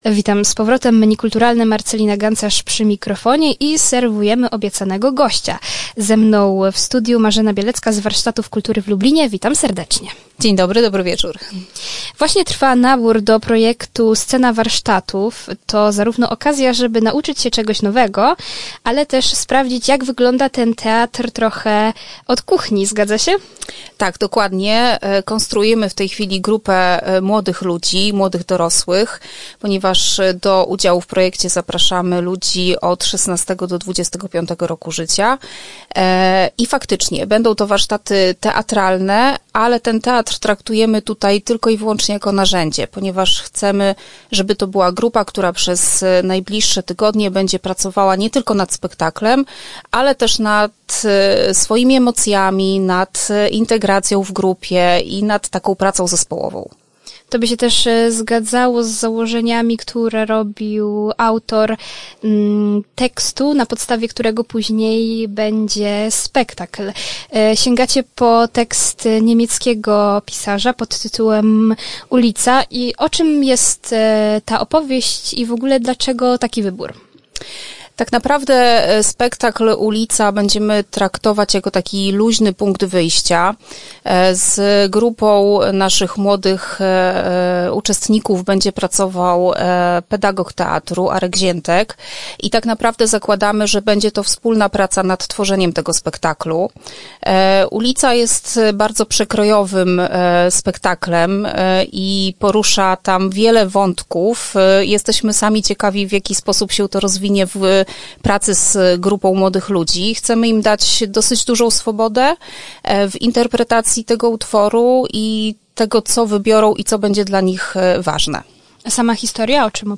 Rozmawialiśmy o nowym projekcie Warsztatów Kultury. Jeśli ktoś ma marzenie, aby zostać aktorem, to nadarza się okazję żeby je zrealizować.